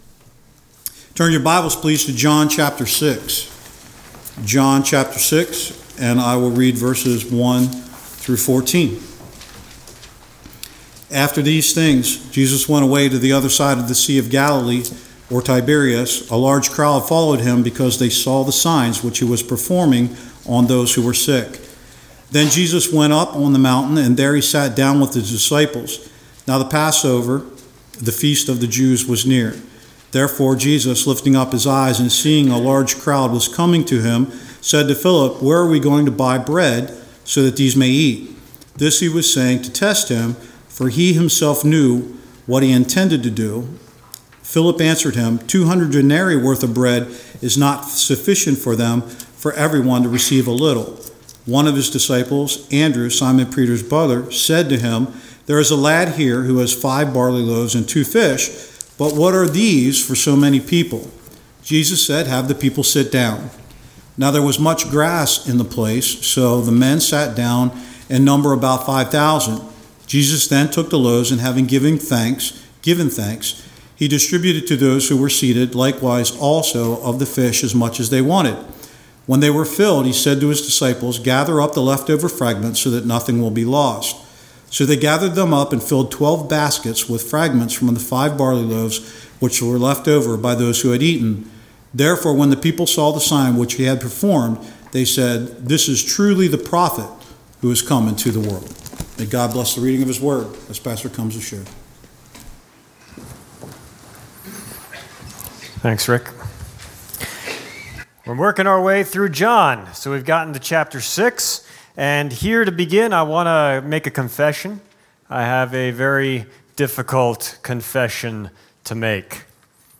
Sermons | New Buffalo Alliance Church